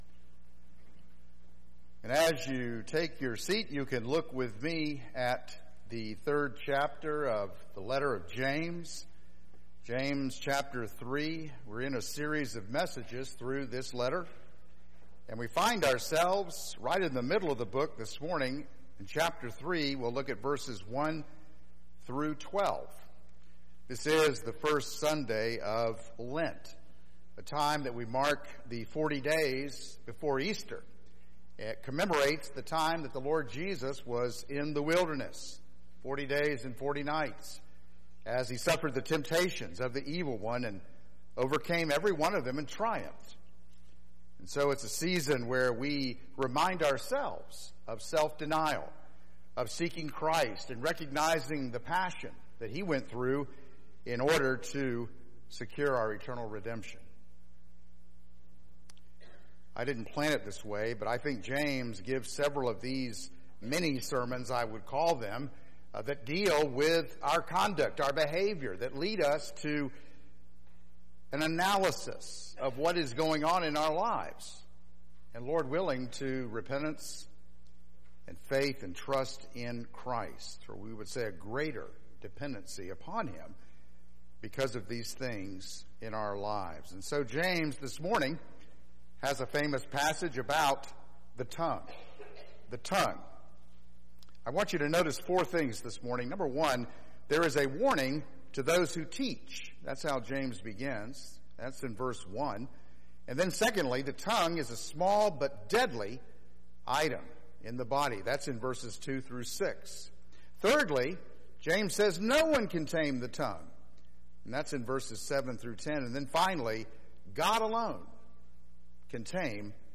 Christ Central Presbyterian Church Sermons